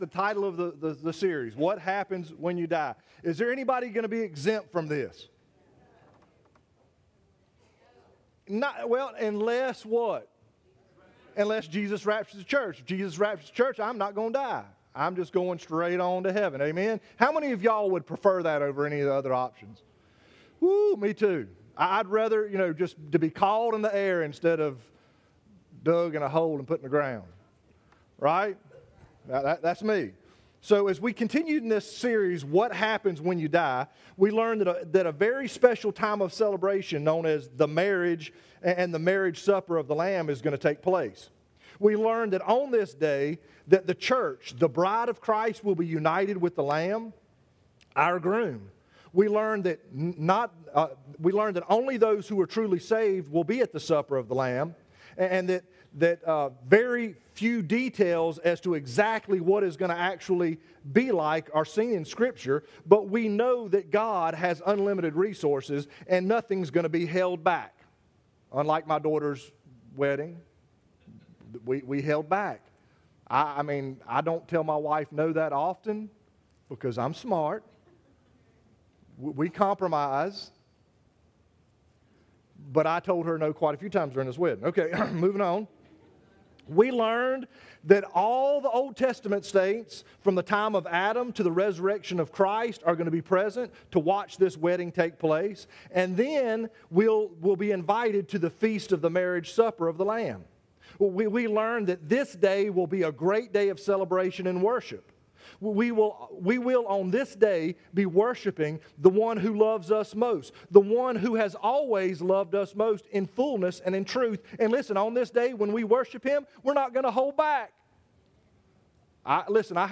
Chevis Oaks Baptist Church Sermons